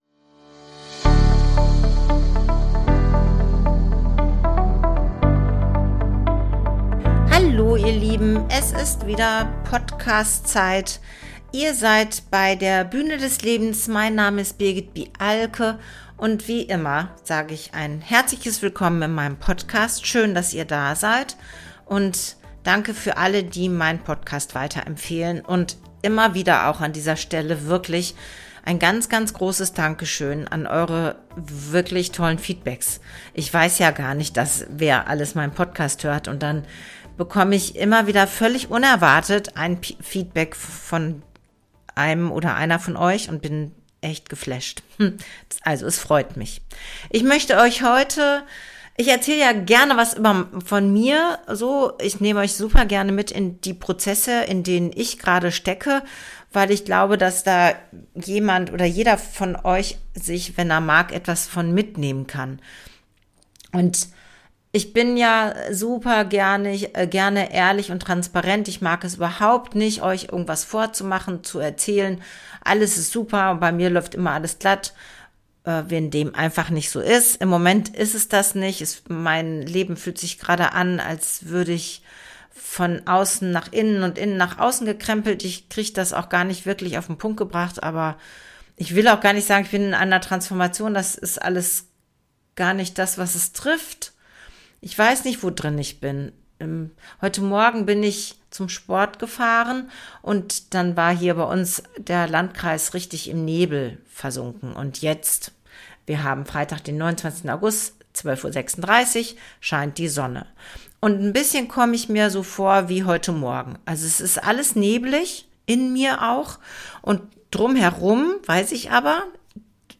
Dazu gibt es wieder ein berührendes Lied – diesmal über den Spiegel, den uns das Leben vorhält, und die Einladung, an der eigenen Nase zu packen.